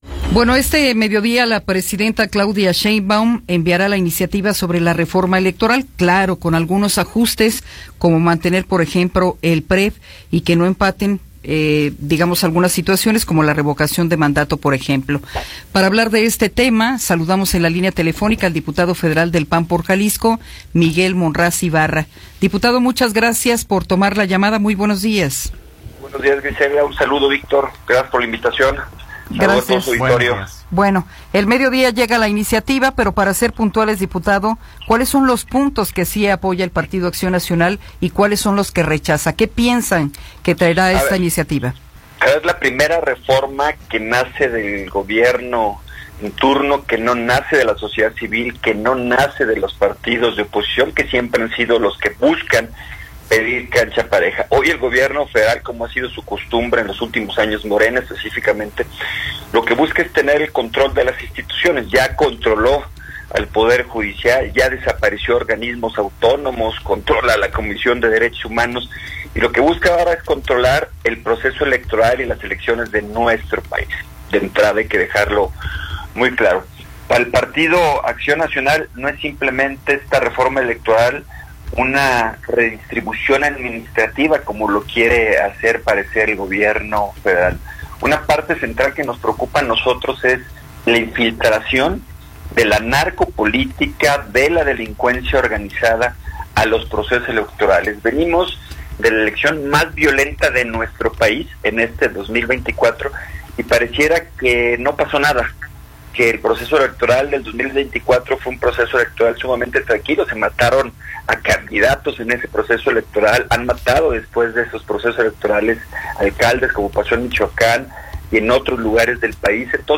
Entrevista con Miguel Ángel Monraz